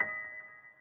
piano28.ogg